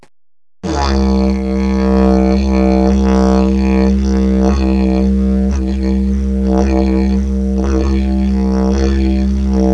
Didgeridoo